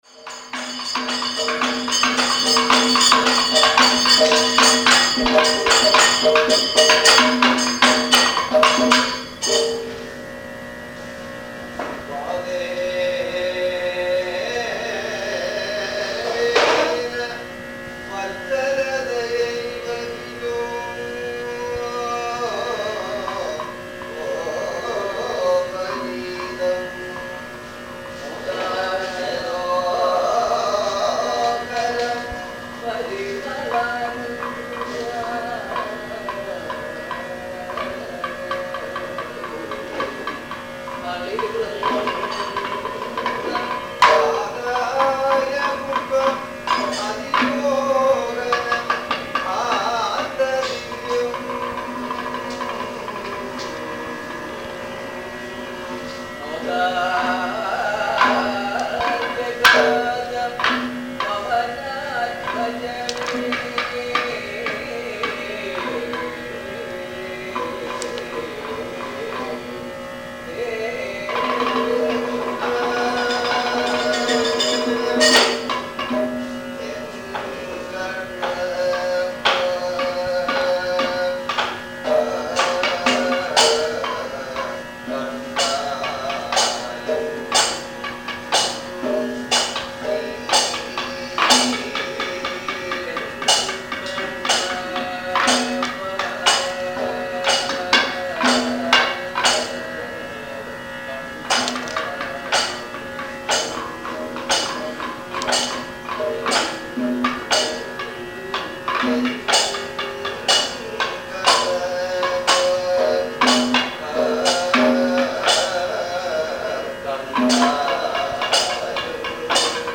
kathakali1.mp3